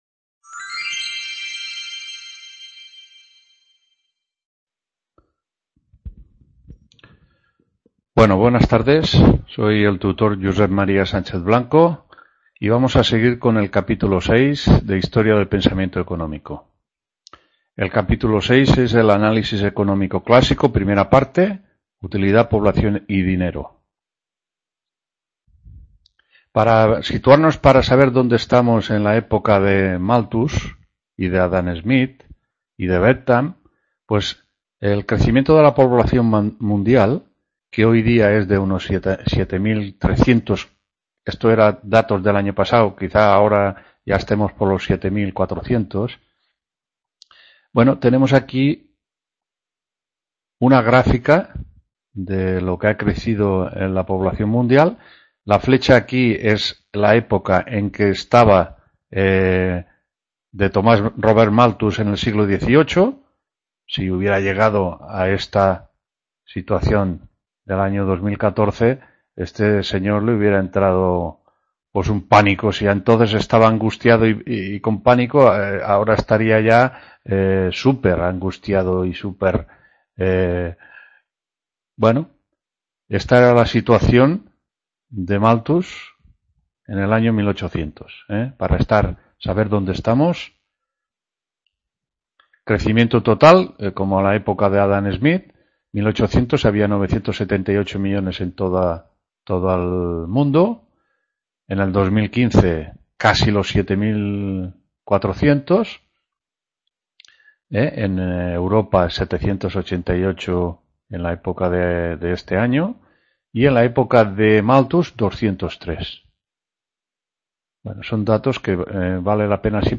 3ª TUTORÍA HISTORIA DEL PENSAMIENTO ECONÓMICO 26-10-15… | Repositorio Digital